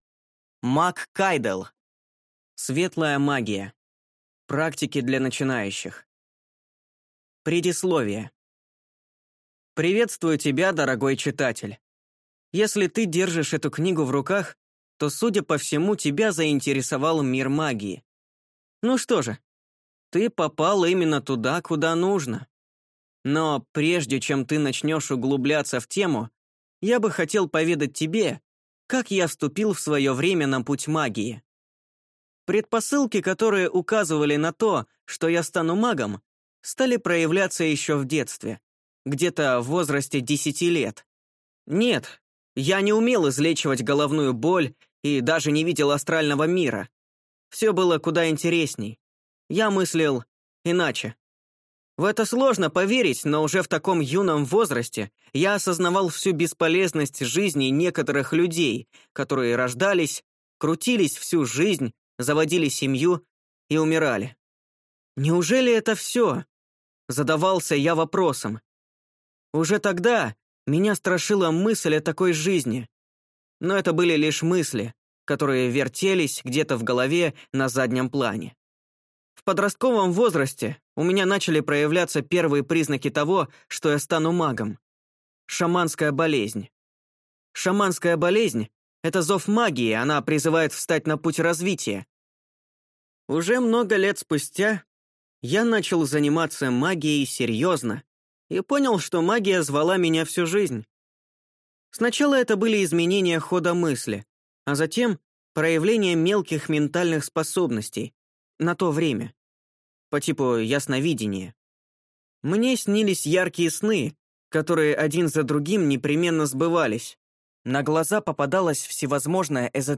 Аудиокнига Светлая магия. Практики для начинающих | Библиотека аудиокниг